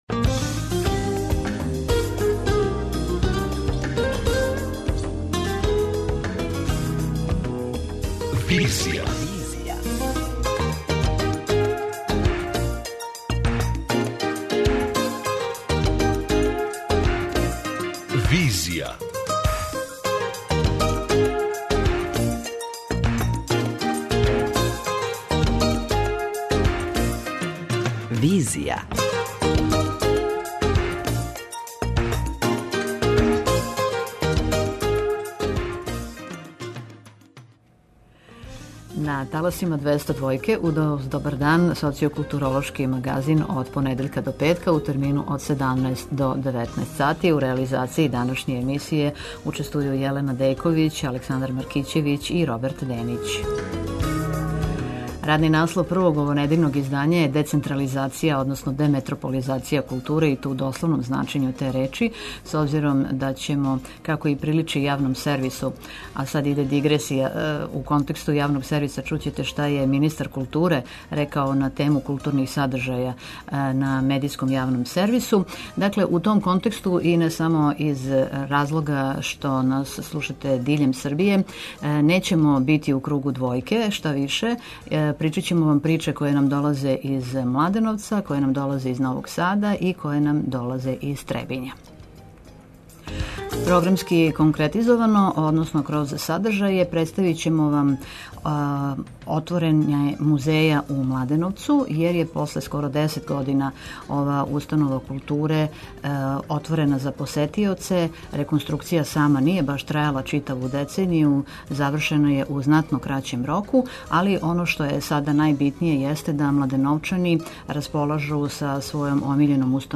преузми : 53.94 MB Визија Autor: Београд 202 Социо-културолошки магазин, који прати савремене друштвене феномене.